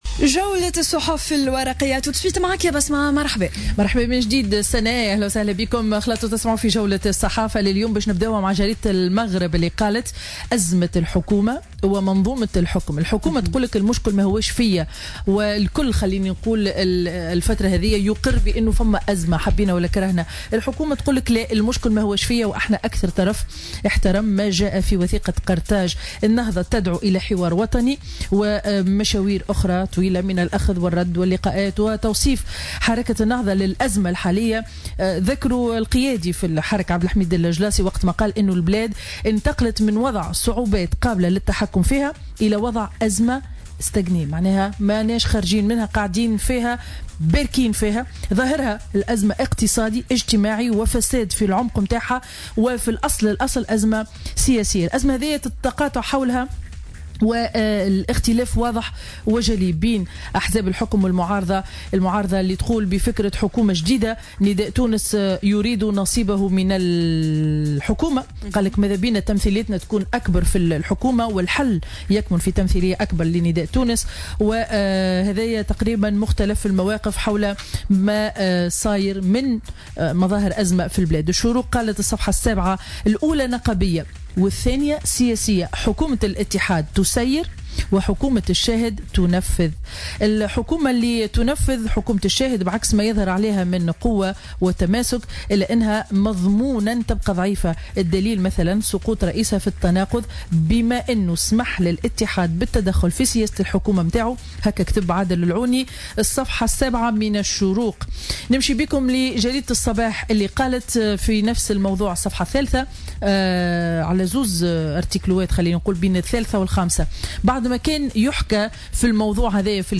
Revue de presse du jeudi 04 mai 2017